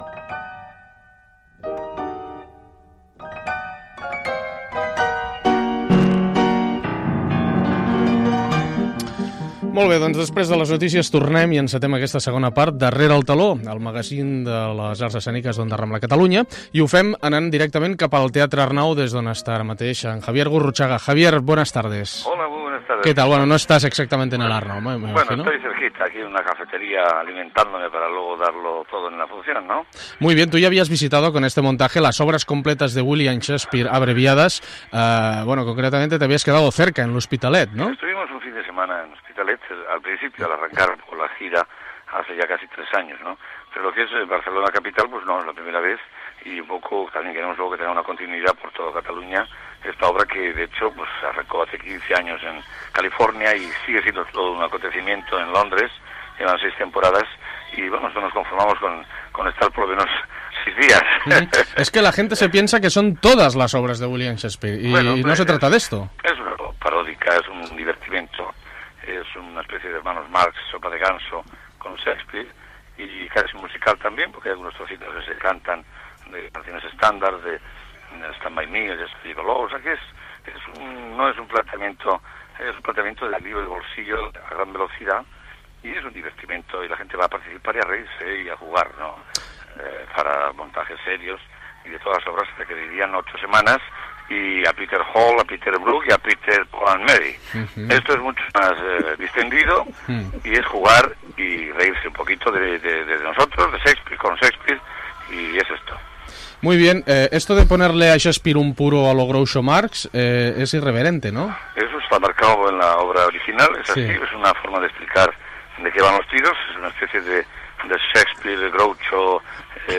Inici de la segona hora del programa i entrevista a Javier Gurruchaga que presenta una obra al Teatre Arnau